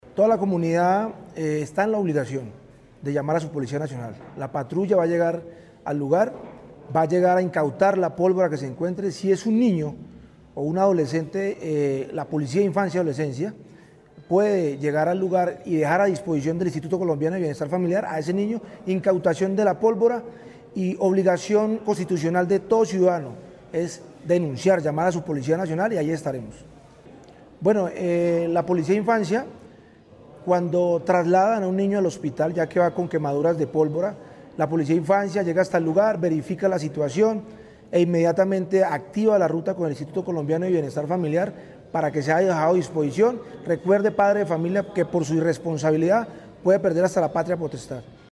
La Gobernación de Caldas, en articulación con la Dirección Territorial de Salud de Caldas (DTSC), el Instituto Colombiano de Bienestar Familiar (ICBF) y la Policía Nacional, realizó el lanzamiento oficial de la campaña departamental de prevención del uso de pólvora para la temporada 2025.